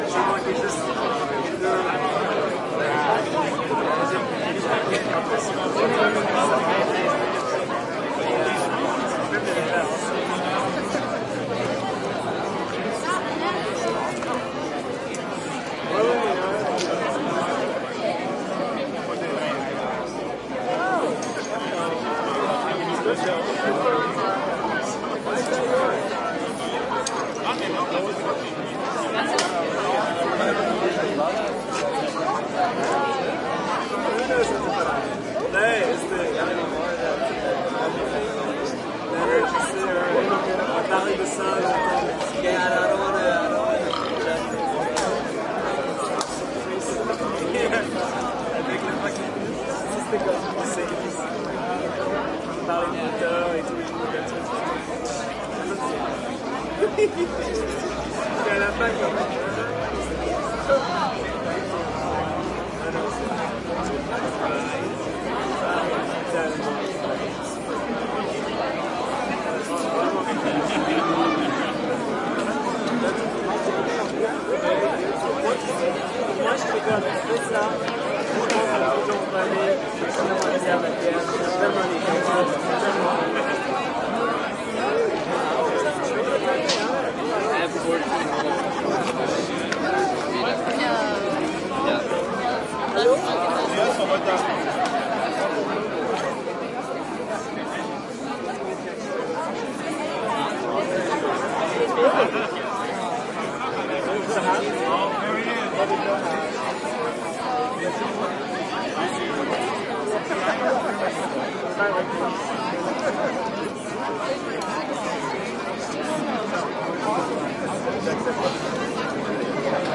蒙特利尔 " 人群中的年轻人停车场聚会 Hotel2 Tango4 不太拥挤的蒙特利尔，加拿大
描述：人群分机中等年轻成人停车场聚会Hotel2Tango4少包装蒙特利尔，Canada.flac
Tag: 蒙特利尔 派对 Hotel2Tango 停车 很多 内线 年轻的时候 人群 成人 加拿大